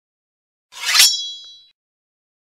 Sword Unsheathed